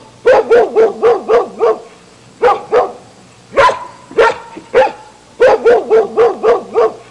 Black Lab Sound Effect
black-lab.mp3